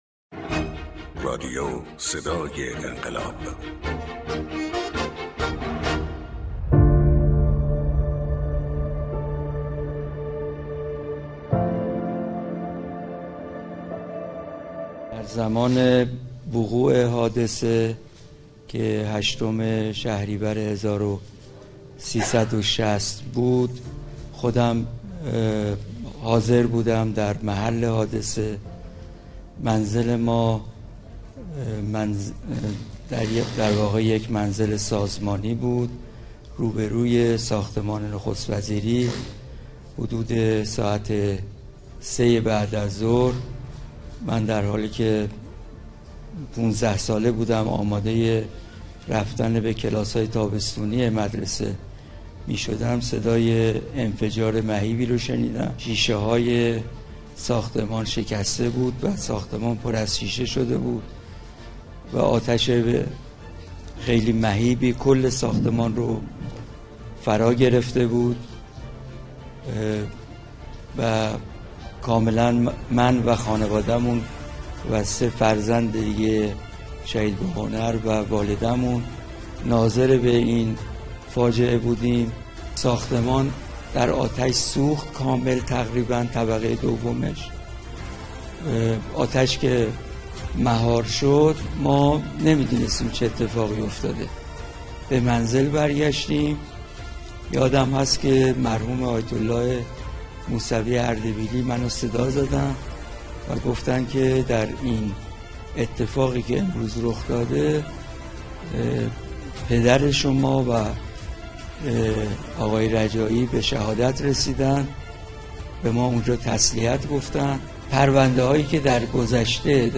در بیست و یکمین دادگاه رسیدگی به جنایات منافقین